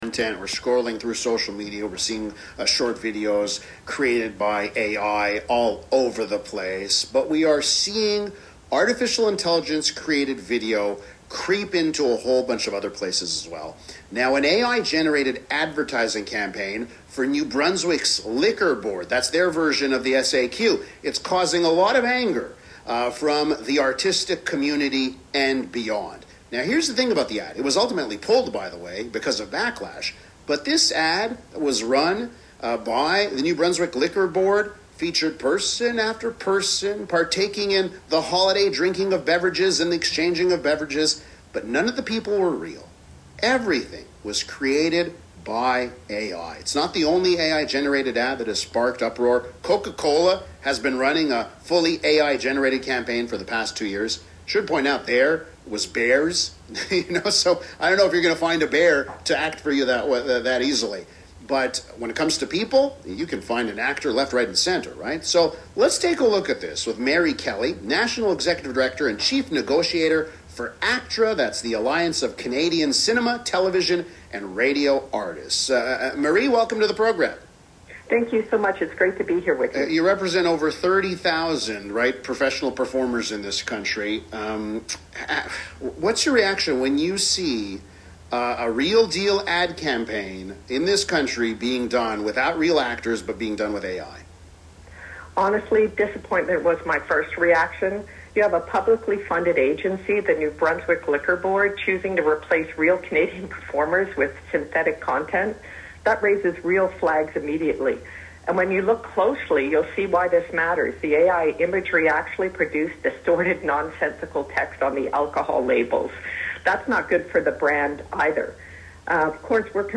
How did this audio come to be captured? on Montreal’s CJAD 800 talk radio